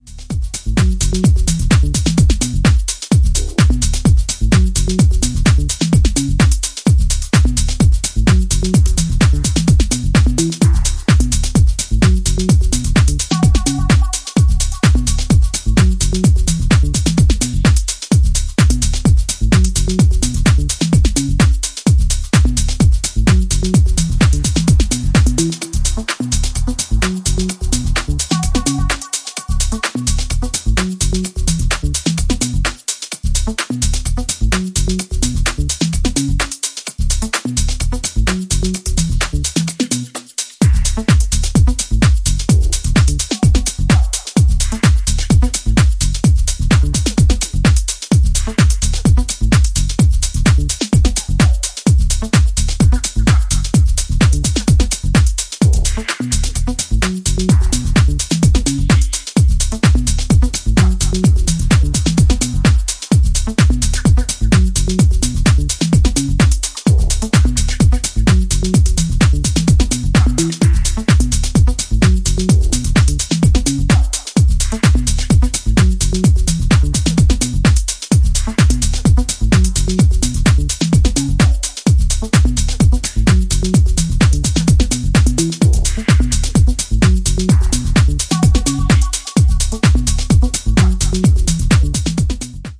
ジャンル(スタイル) MINIMAL / TECH HOUSE / DEEP HOUSE